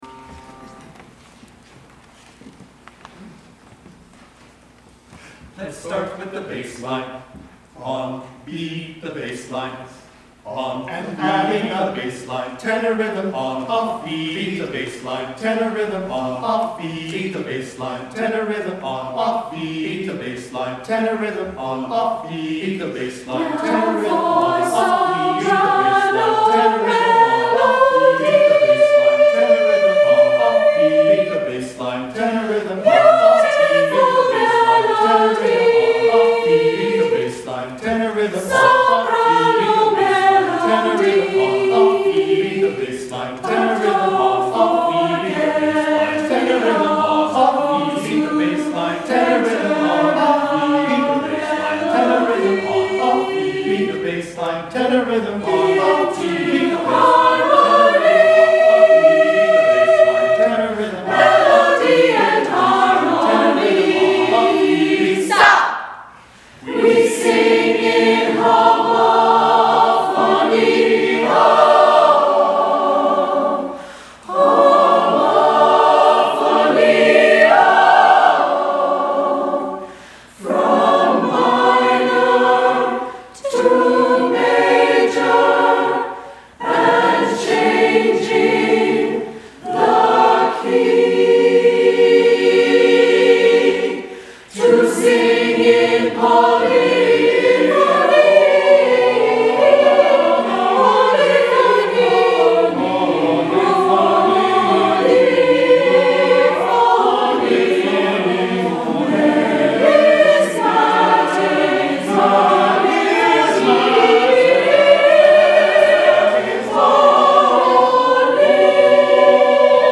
Below you will find select audio and video from our past performances for your listening and viewing enjoyment.
From our April 7, 2018 concert, Sharing Our Choral Art VI: